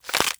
BREAK_Hollow_stereo.wav